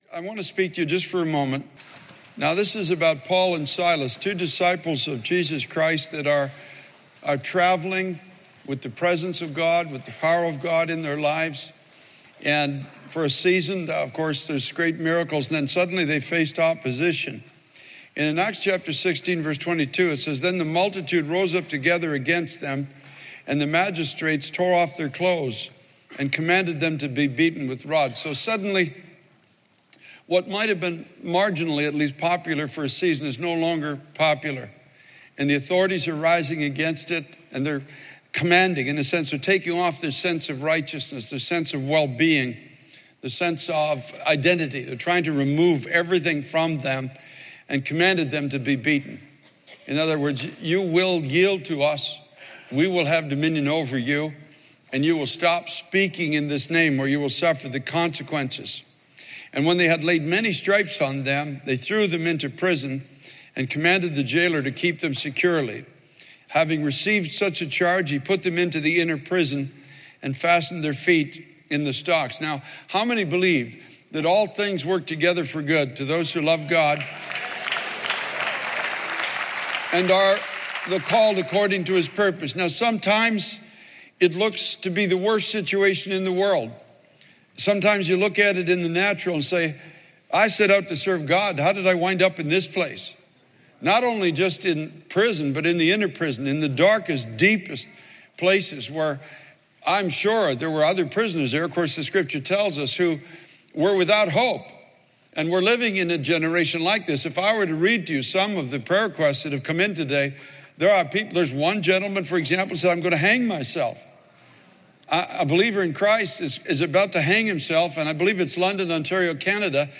Thoughts On Faith and Prayer | Times Square Church Sermons
Our messages are recorded at Times Square Church in New York City.